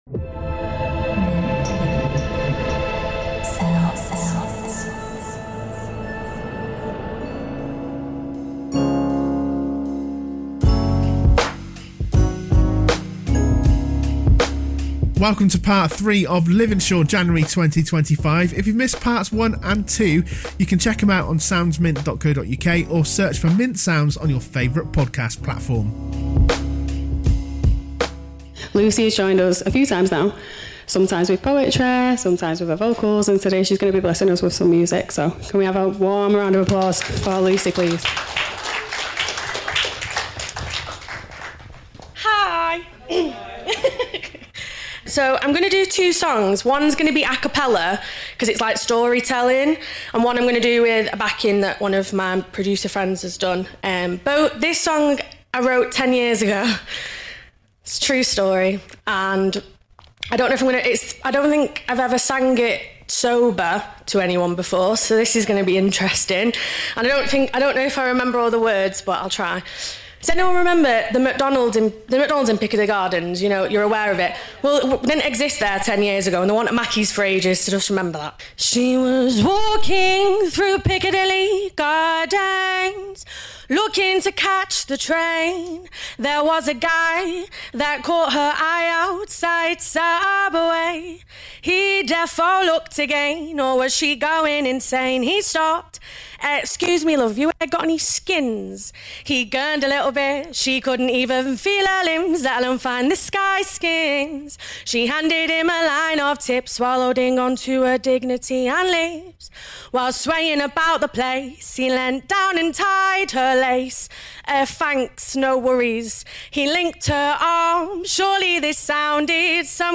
It's the final part of Liveinshawe's January 2026 show. This part is all about the music, with three artists taking up the third part. There was fun, technical problems but some real talent throughout the whole event.